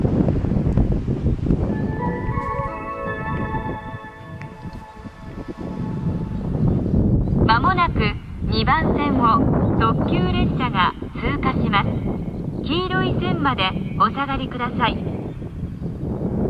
浜野２番線接近放送　　特急通過放送です。